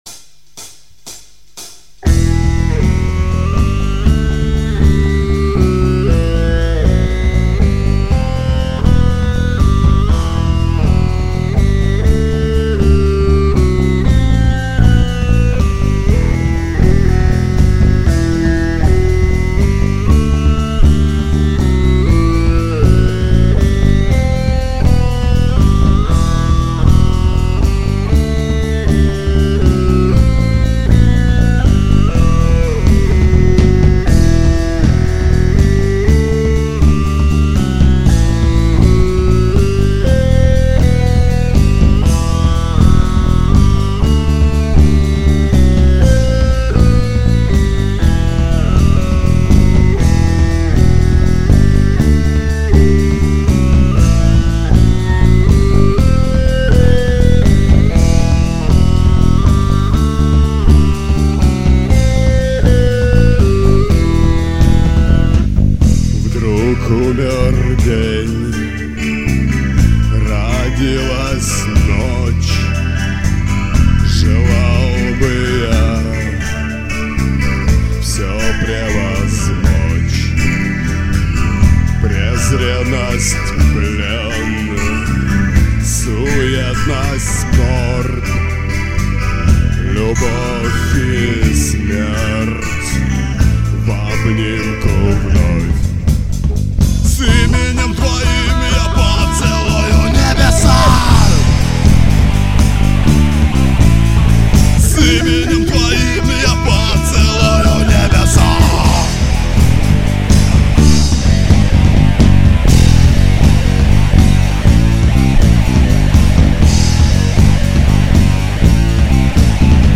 Музыкальный хостинг: /Панк